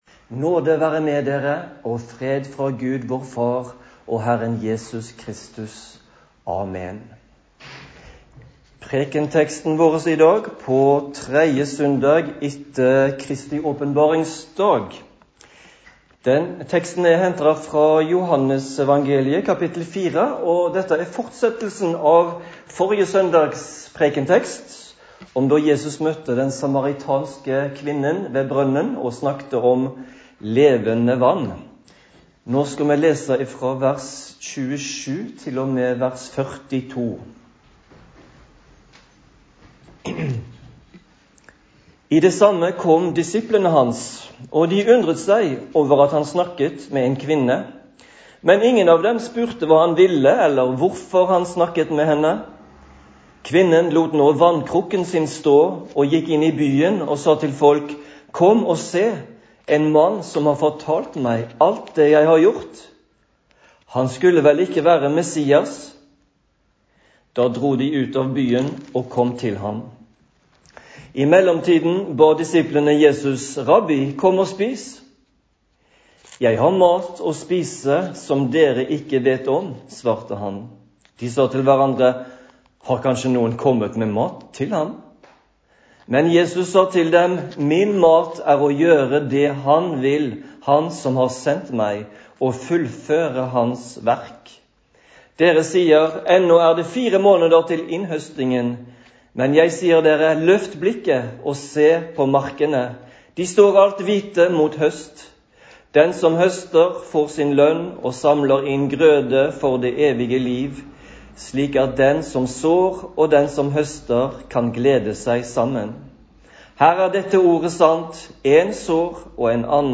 Preken på 3. søndag etter Kristi åpenbaringsdag